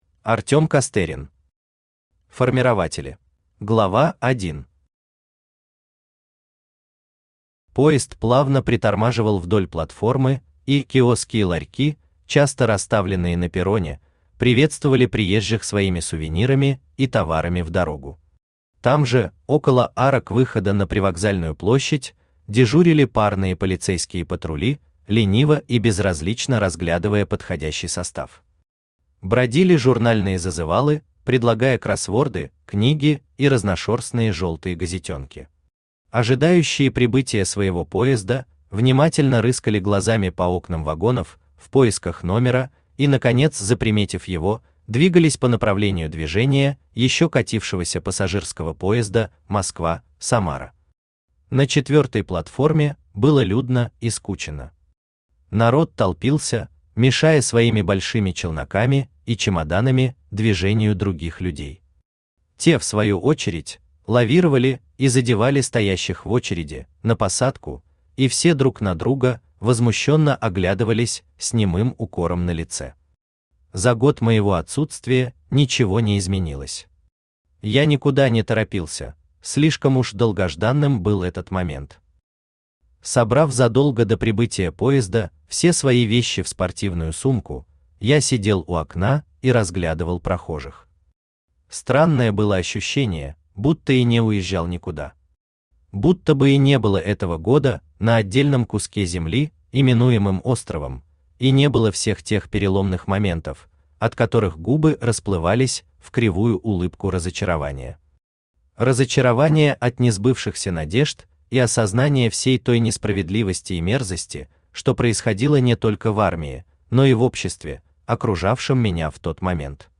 Аудиокнига Формирователи | Библиотека аудиокниг
Aудиокнига Формирователи Автор Артём Андреевич Костерин Читает аудиокнигу Авточтец ЛитРес.